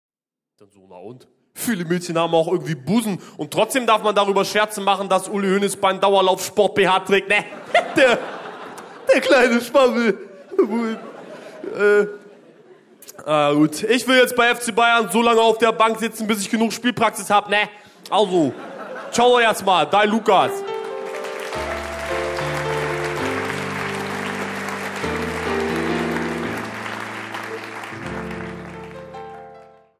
Live-Mitschnitt
Jan Böhmermann (Sprecher)
Live-Mitschnitt aus dem Gloria, Köln. Eine Veranstaltung des Westdeutschen Rundfunks Köln, 2009